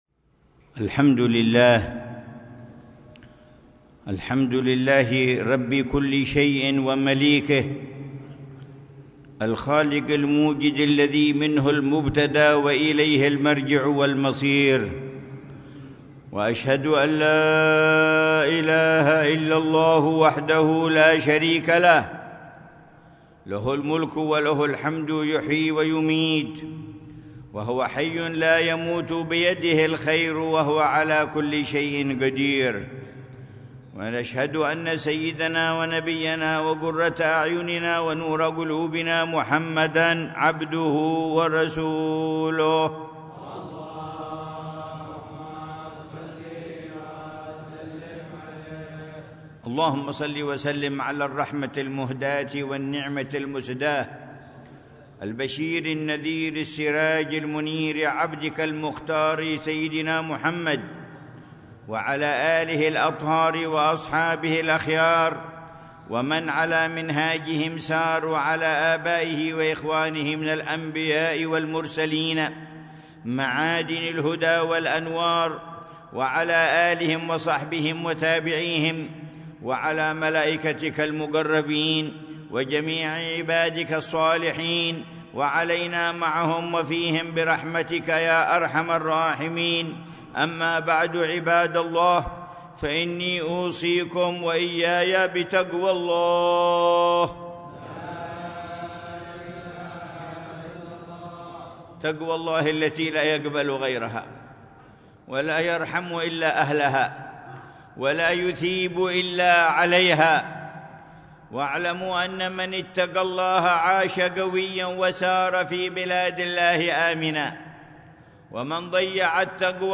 خطبة الجمعة
في جامع حوطة الإمام أحمد بن زين، مديرية شبام، وادي حضرموت